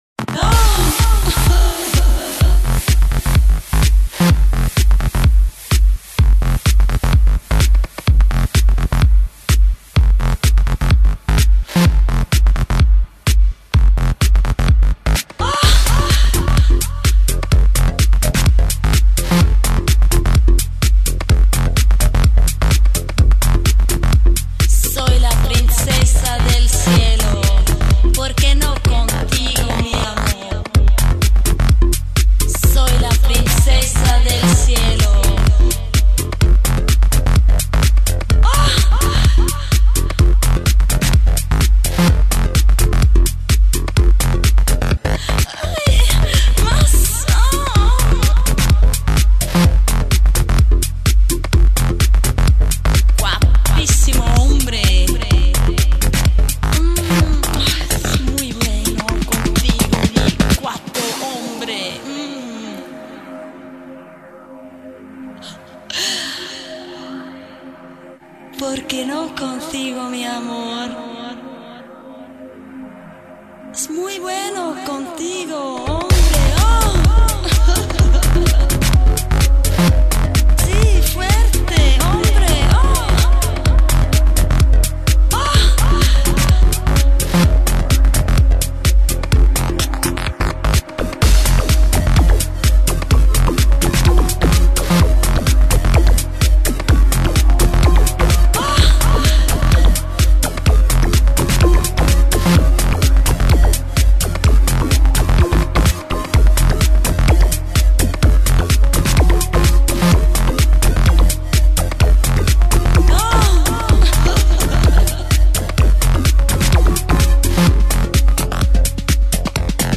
89.1 Стиль HOUSE
Единственный минус качество звука 112, пришлось уменьшить.